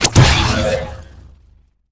sci-fi_shield_power_on_impact_01.wav